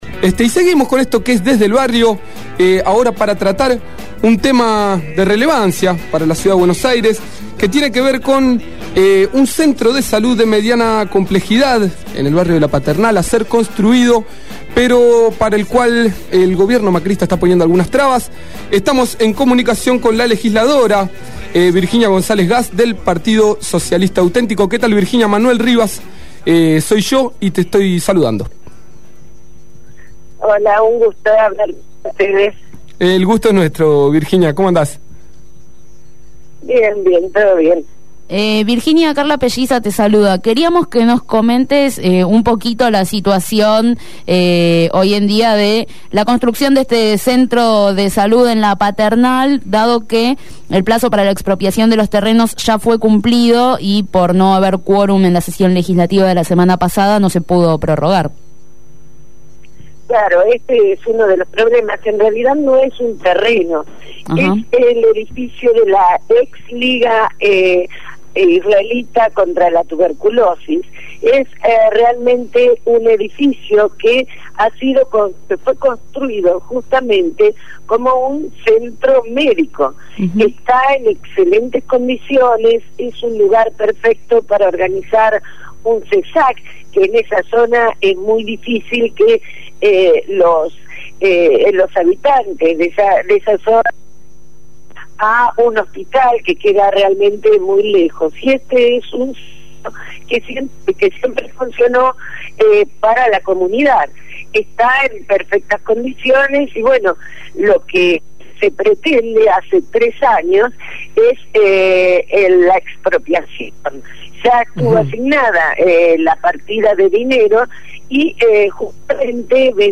Virginia González Gass, legisladora porteña por el Partido Socialista Auténtico, habló en Desde el Barrio.